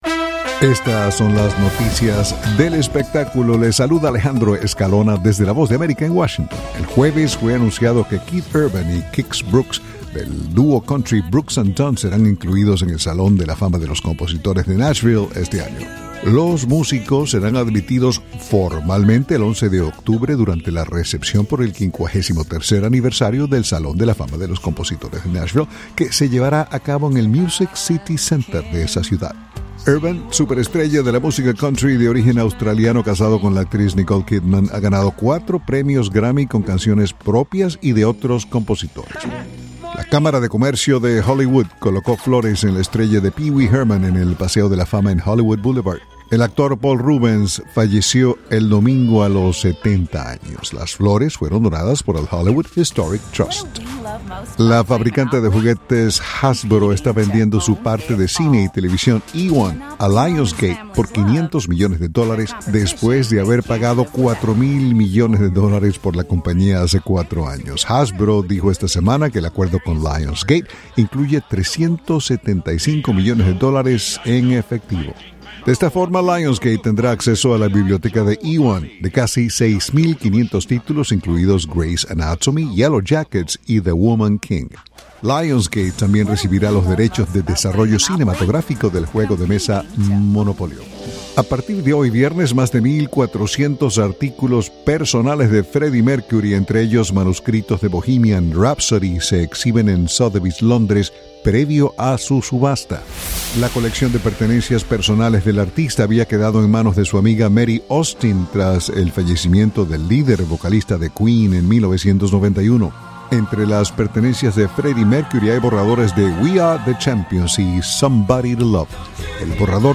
Son las noticias del espectáculo